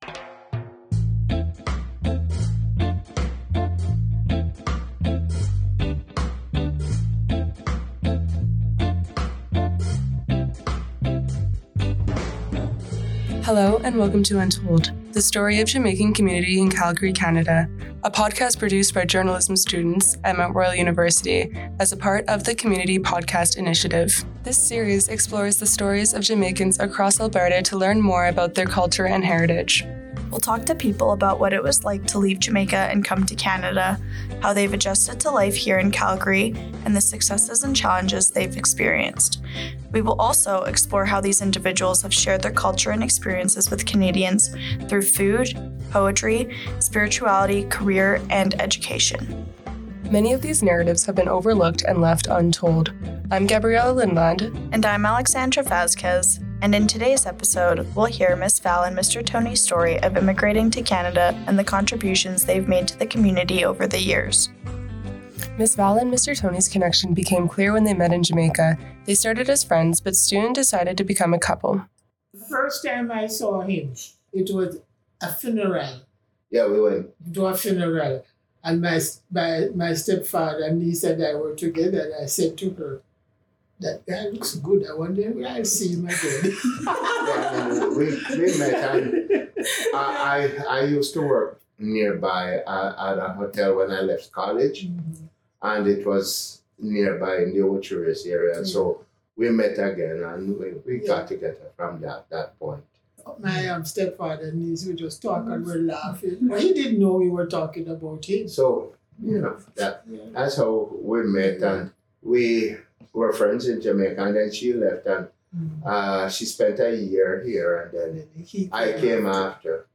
This series was produced by journalism students at Mount Royal University in Calgary, as part of the Community Podcast Initiative.